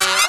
RIFFFFFF.wav